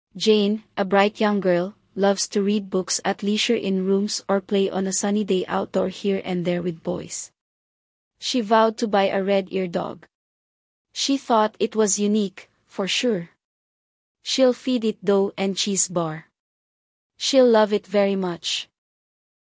Pangram_en-PH-RosaNeural_Jane, a bright.mp3.mp3